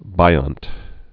(bīŏnt)